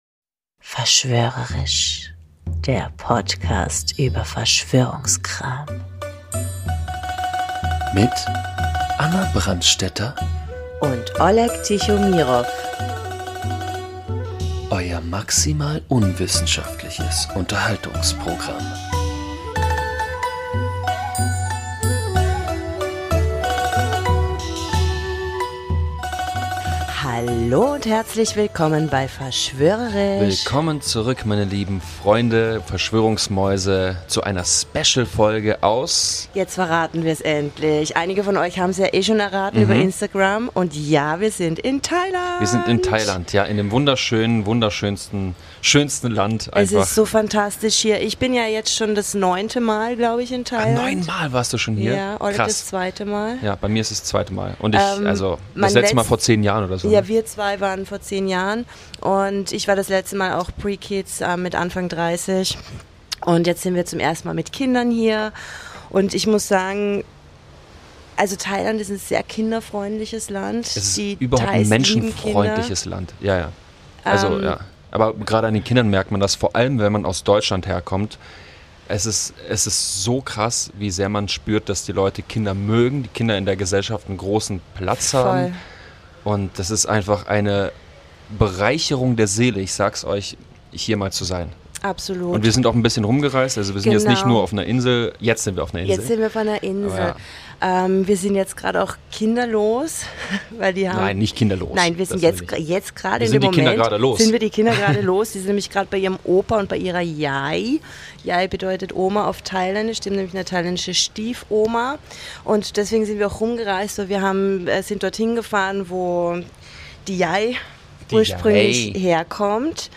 Wir melden uns aus dem Urlaub in Thailand mit einer Sage, die dort eng mit dem Buddhismus verwoben ist: die Erzählung von Siddhartha Gautama (besser bekannt als Buddha) und dem Naga-König Mucalinda.
Und natürlich versuchen wir auch diese Geschichte im Kontext unseres Podcasts zu betrachten - denn wer sagt denn, dass die Naga nicht vielleicht auch zur Kategorie der Reptiloiden gehören? Achtung Triggerwarnung: das Meeresrauschen im Hintergrund kann bei manchen akutes Fernweh auslösen!